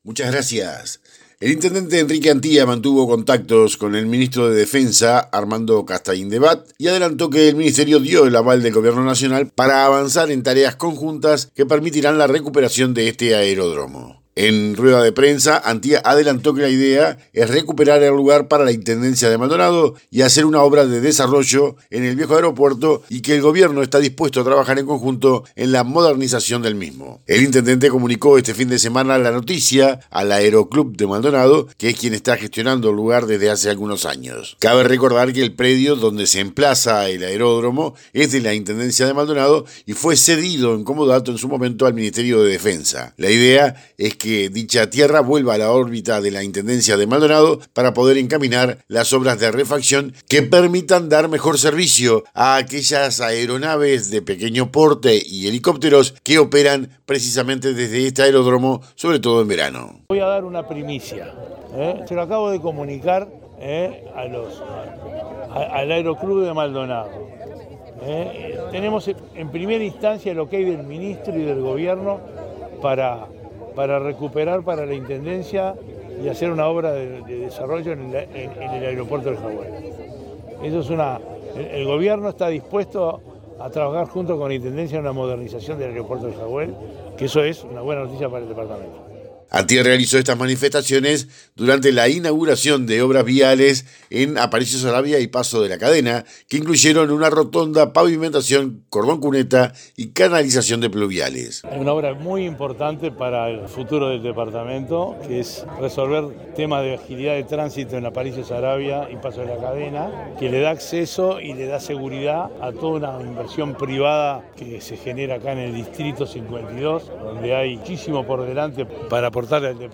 En rueda de prensa, Antía adelantó que la idea es recuperar el lugar para la Intendencia de Maldonado y hacer una obra de desarrollo en el viejo aeropuerto.
informe-Jaguel.mp3